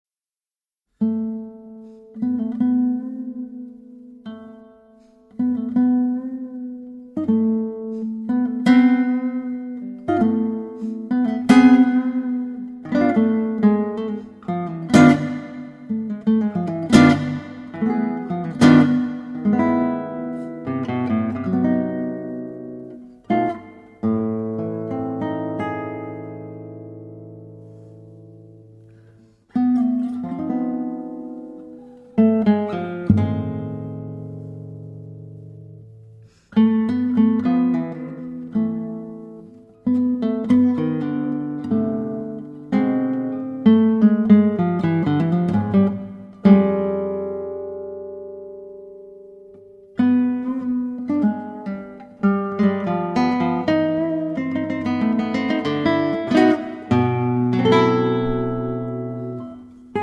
Guitar
Oud